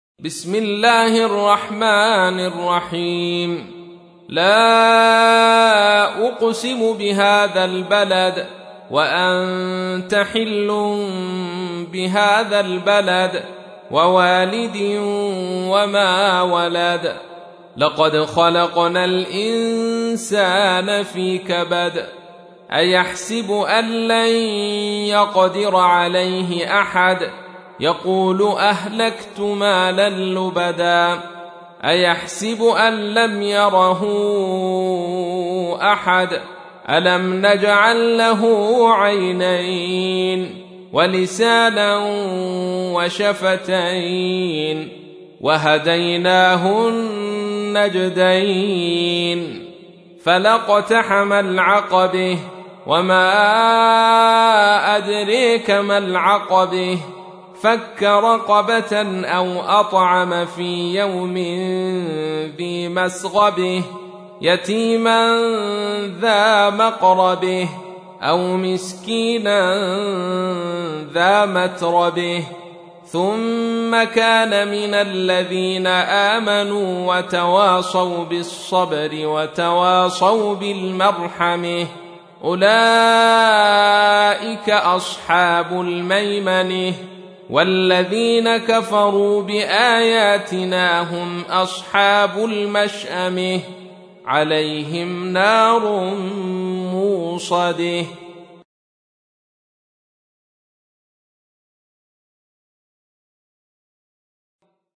تحميل : 90. سورة البلد / القارئ عبد الرشيد صوفي / القرآن الكريم / موقع يا حسين